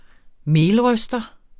Udtale [ -ˌʁœsdʌ ]